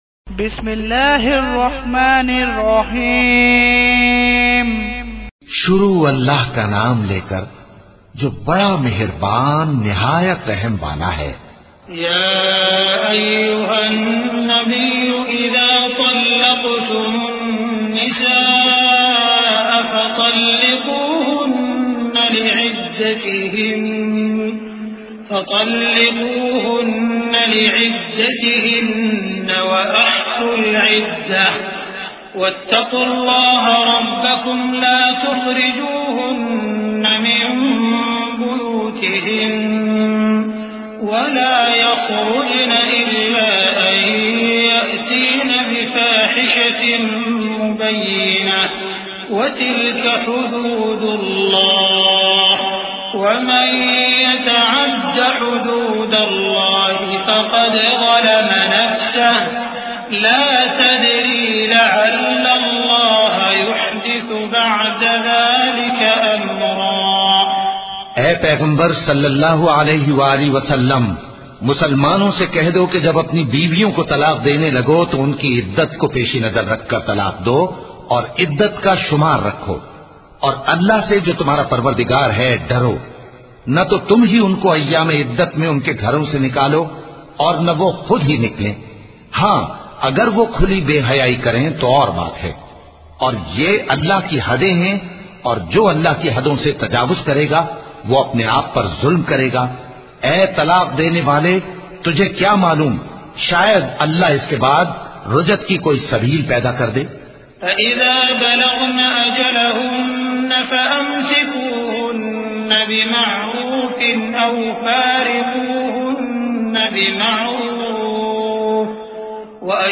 Listen online and download mp3 tilawat of Surah Talaq with urdu translation.